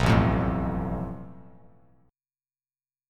Asus2#5 chord